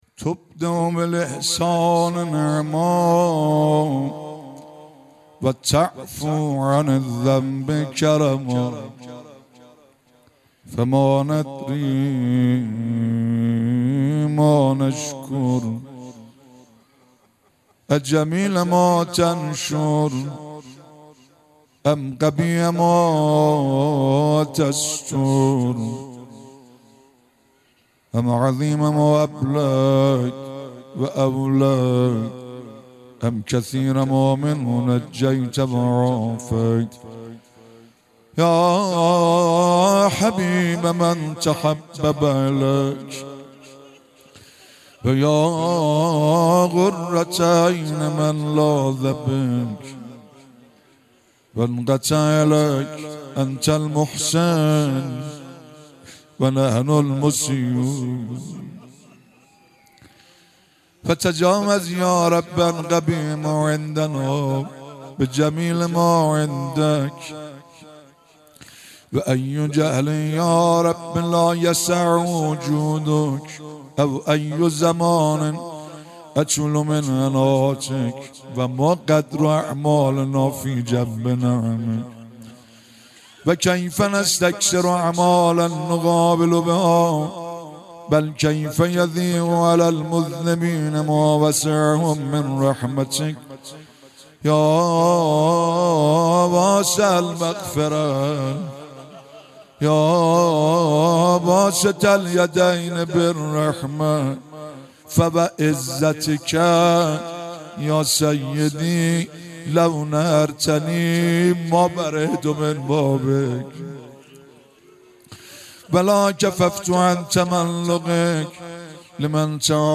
شب پنجم ماه مبارک رمضان با نوای گرم
در حرم حضرت فاطمه معصومه(س)